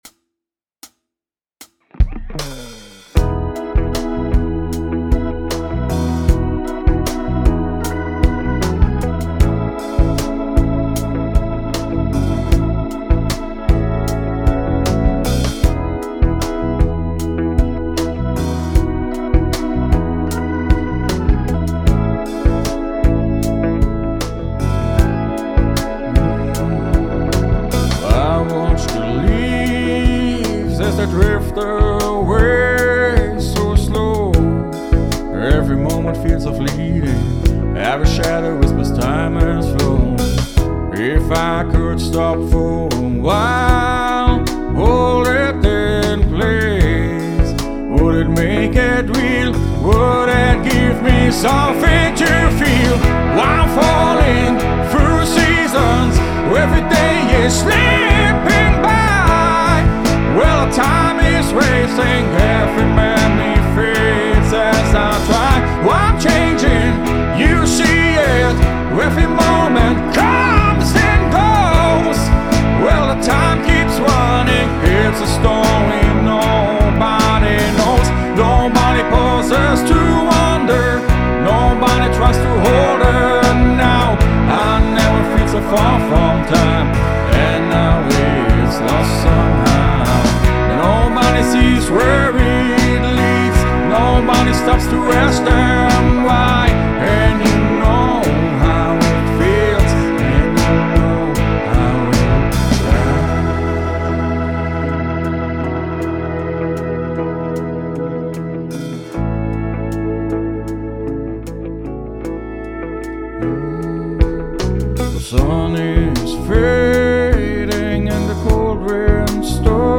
Falling through Seasons [Rock / Pop]